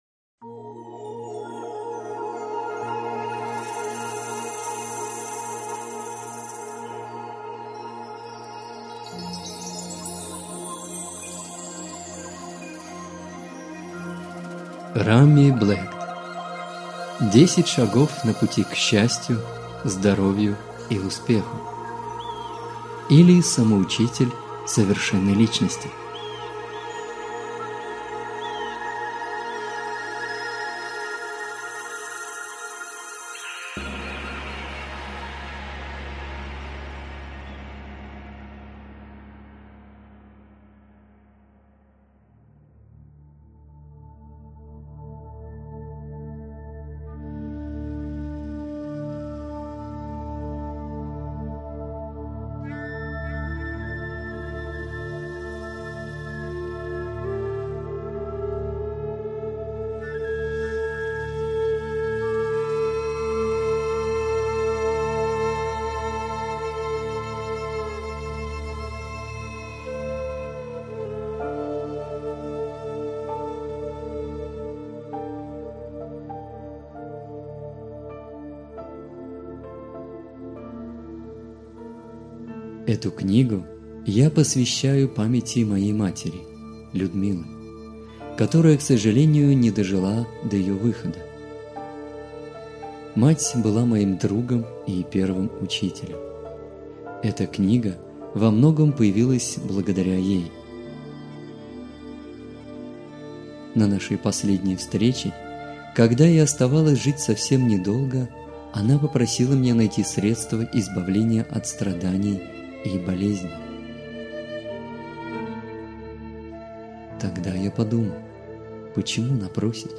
Аудиокнига Самоучитель совершенной личности. 10 шагов на пути к счастью, здоровью и успеху | Библиотека аудиокниг